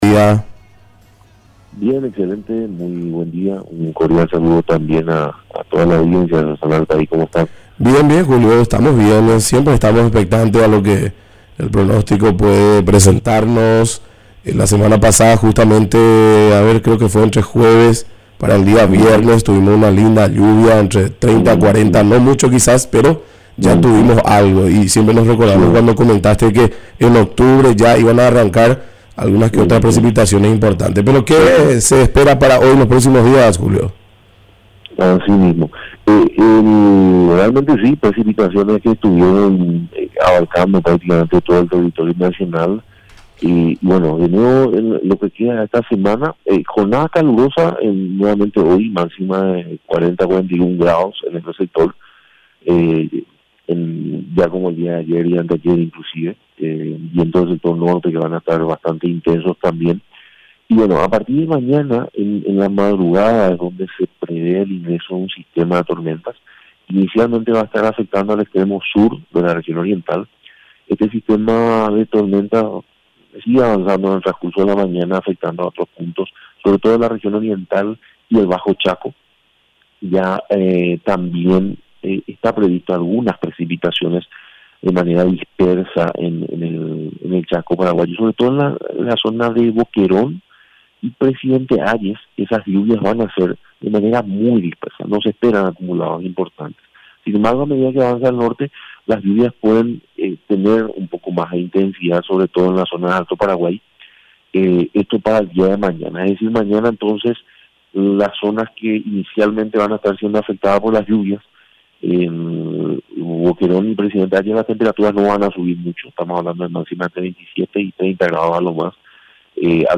Entrevistas / Matinal 610 Informe meteorológico e hidrológico Oct 23 2024 | 00:11:33 1x 00:00 / 00:11:33 Subscribe Share RSS Feed Share Link Embed